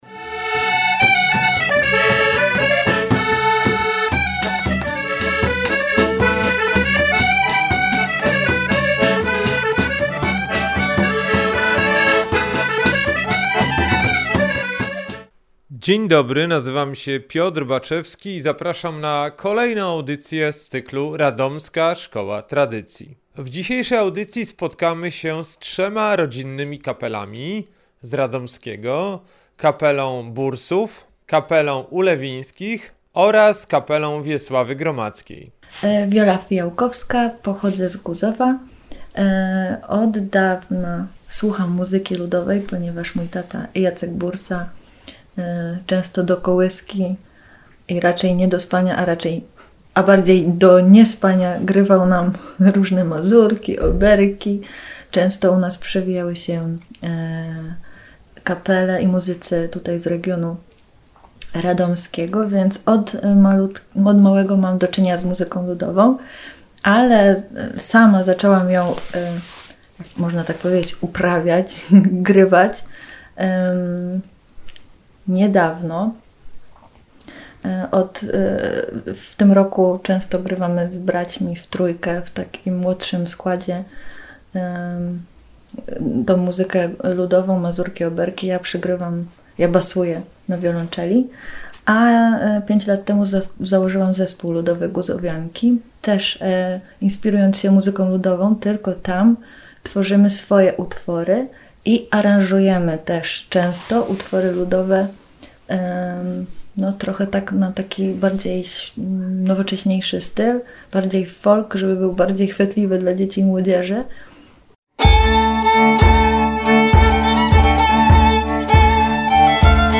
Dziś w audycji Radomska Szkoła Tradycji spotkamy się z trzema rodzinnymi kapelami spod Radomia grającymi muzykę tradycyjną
12-Muzyka-spod-Radomia-cz.-2.mp3